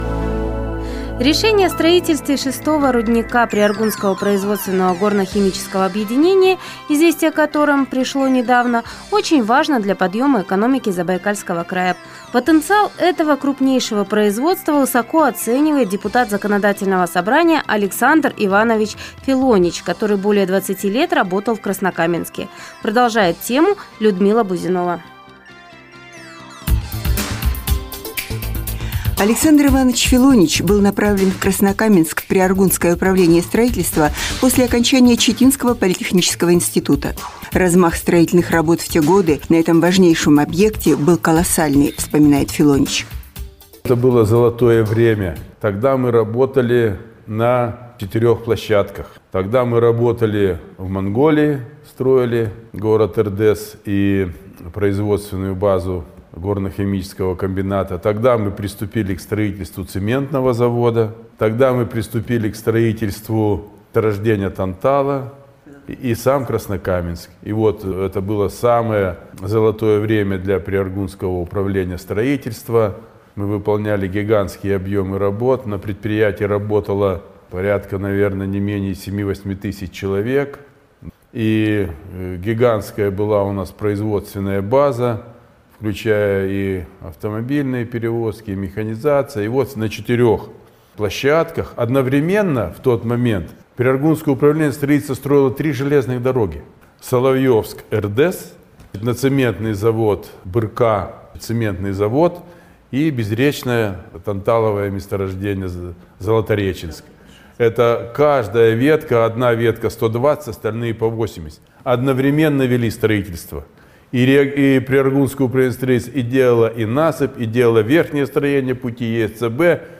Депутат-строитель Александр Филонич – о будущем города Краснокаменска. Эфир "Радио России"